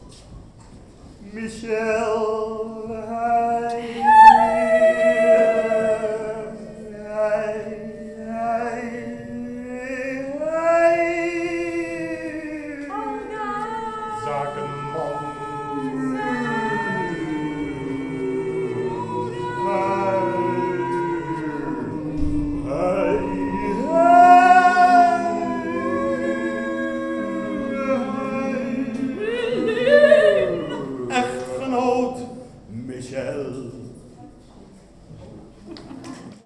music project